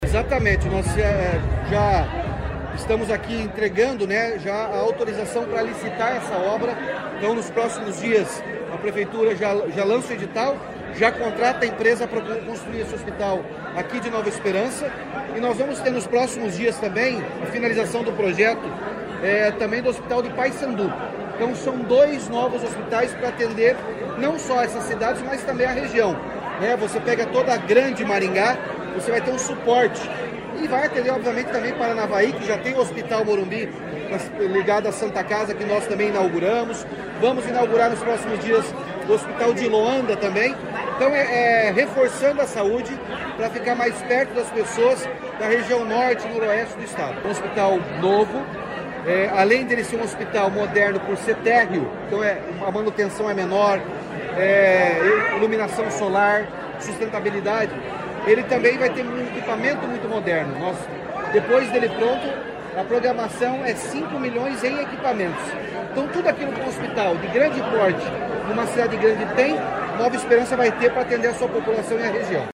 Sonora do governador Ratinho Junior sobre o anúncio do novo hospital de Nova Esperança